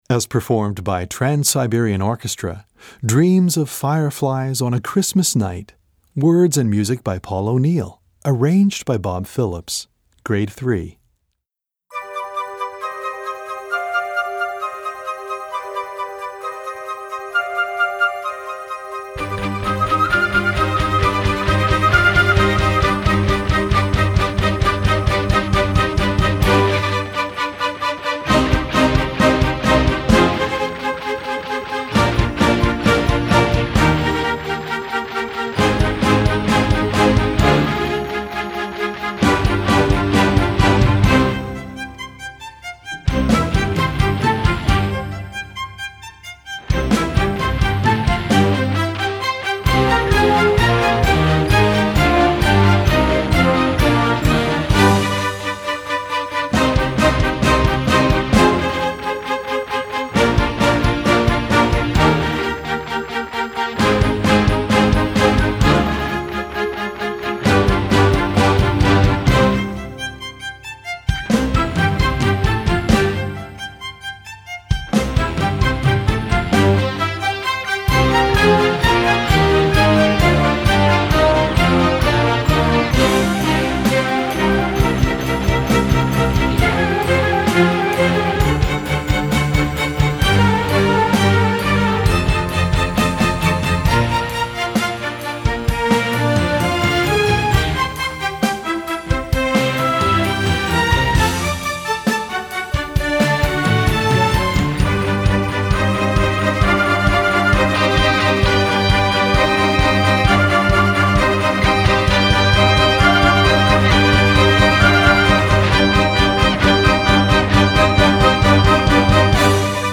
Gattung: Sinfonieorchester
Besetzung: Sinfonieorchester